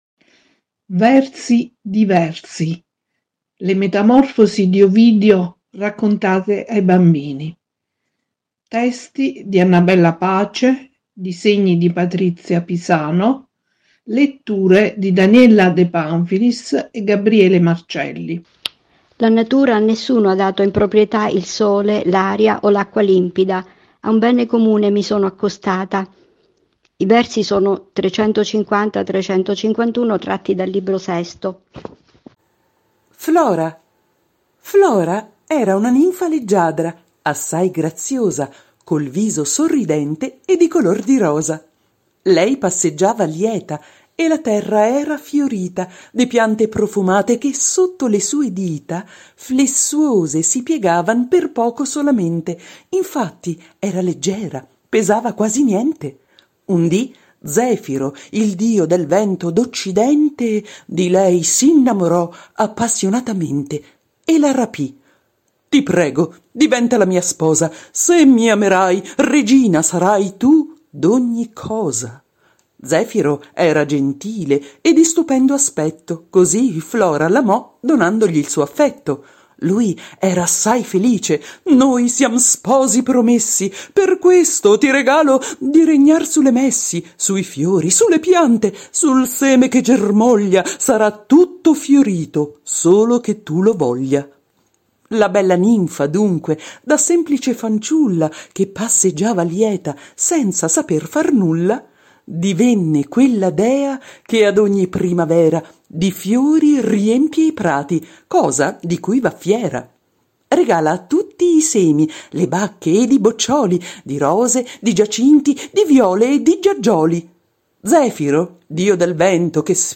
• Estratto dell’audiolibro (mp4 ed